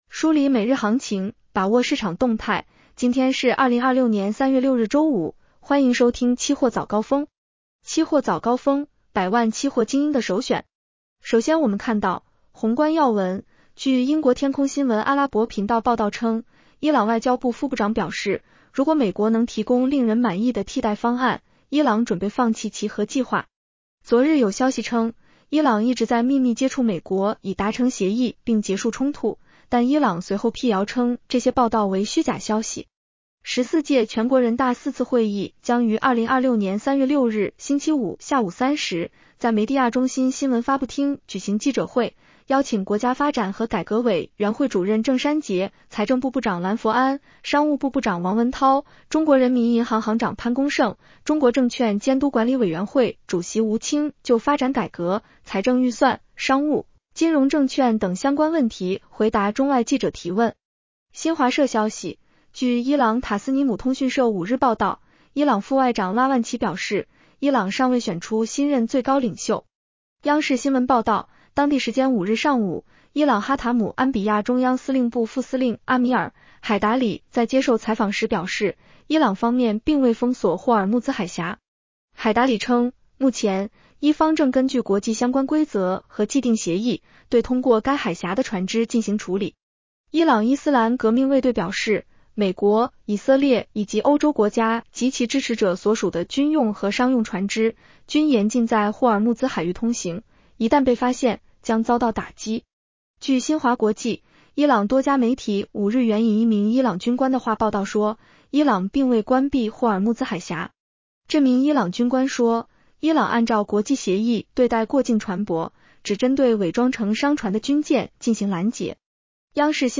期货早高峰-音频版 女声普通话版 下载mp3 热点导读 1.